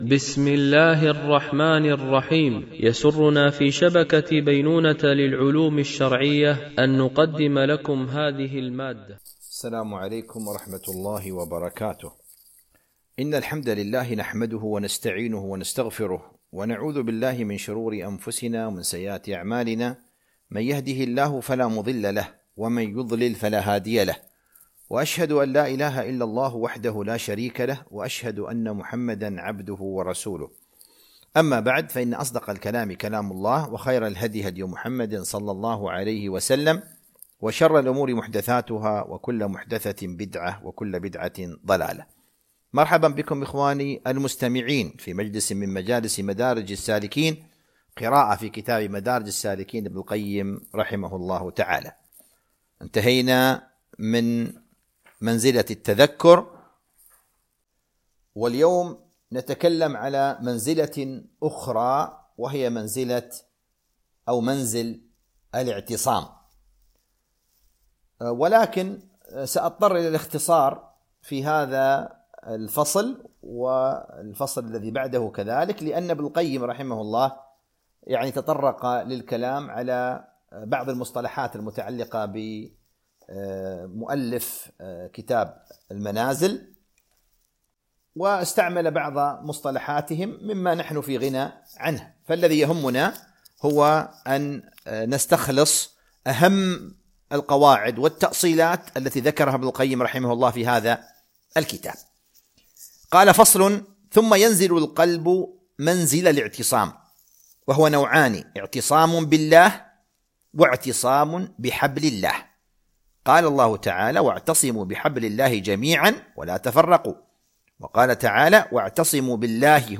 قراءة من كتاب مدارج السالكين - الدرس 50